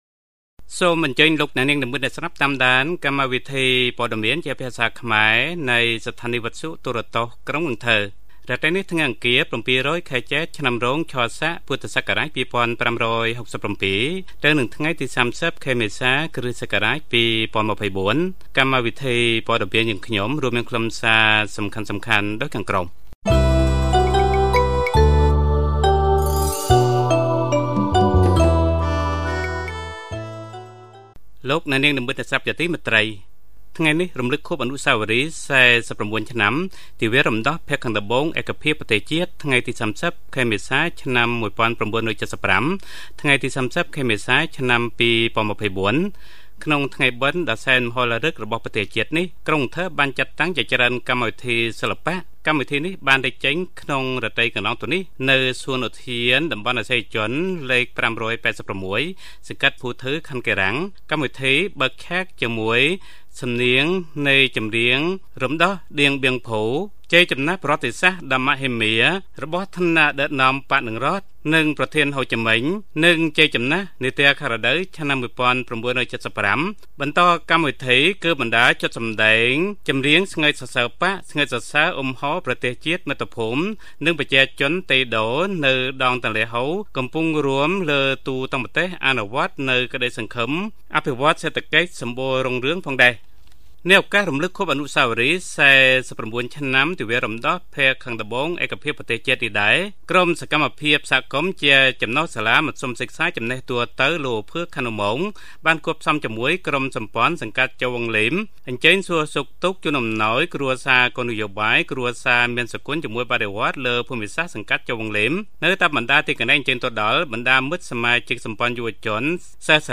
Bản tin tiếng Khmer tối 30/4/2024
Mời quý thính giả nghe Bản tin tiếng Khmer tối của Đài Phát thanh và Truyền hình thành phố Cần Thơ.